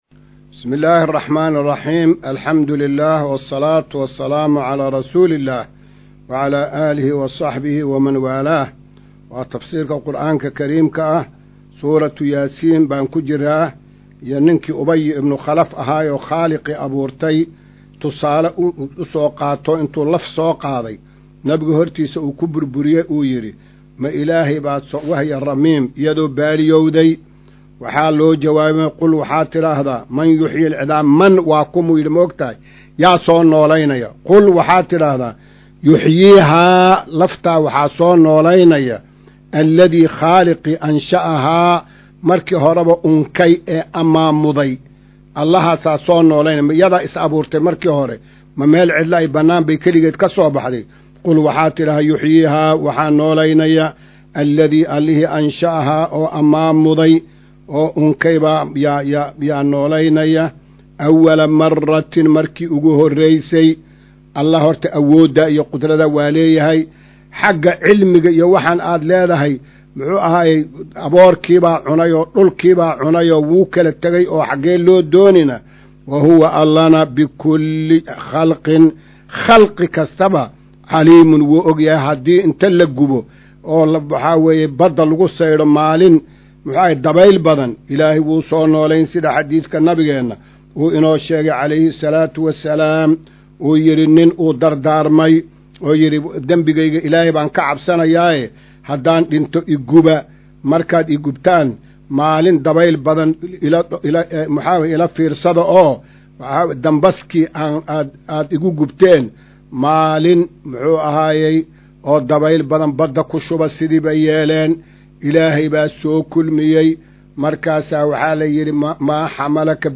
Maqal:- Casharka Tafsiirka Qur’aanka Idaacadda Himilo “Darsiga 211aad”